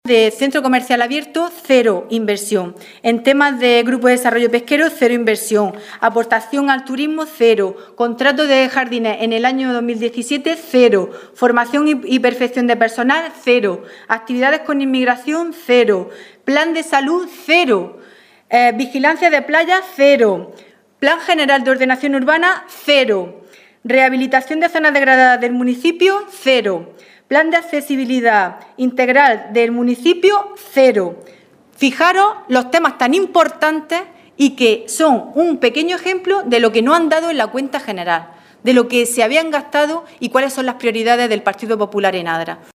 En rueda de prensa, la portavoz del PSOE abderitano se ha referido al “deterioro” de los servicios públicos en el que es necesario, ha dicho, acometer mejoras en las prestaciones que se dan a la ciudadanía, como saneamiento, electricidad, jardinería, etcétera.